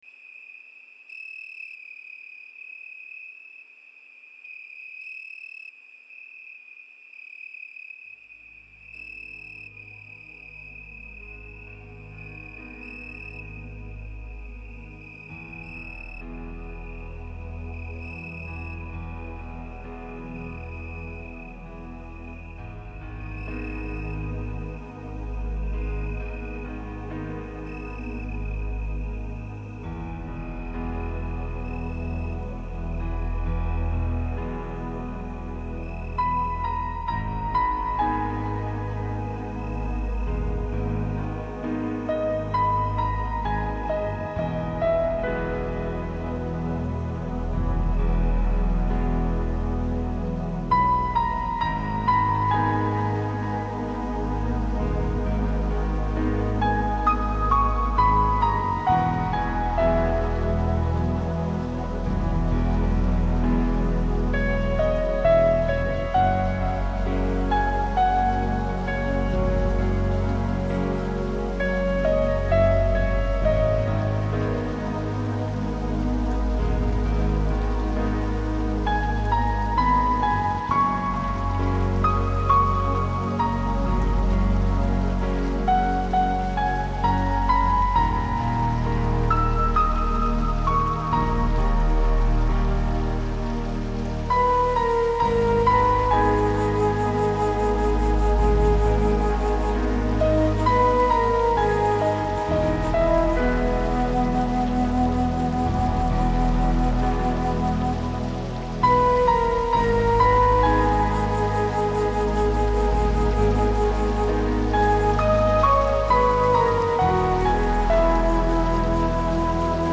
Грустная мелодия
Grustnaya-melodiya.mp3